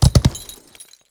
gallop1.wav